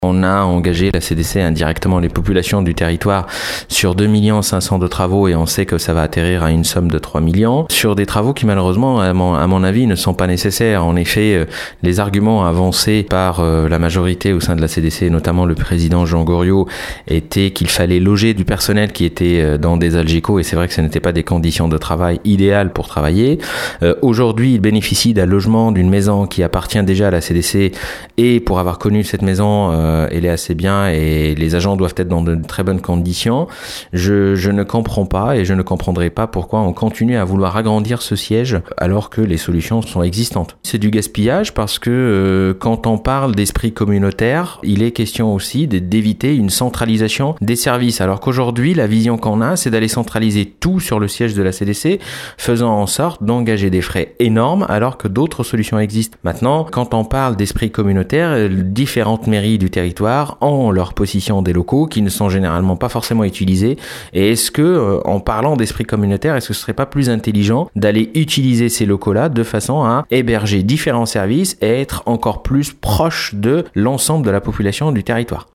On écoute Younes Biar :